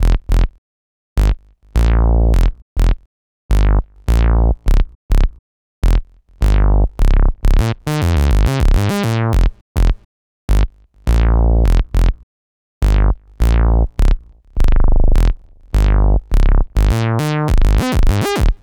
Bass 41.wav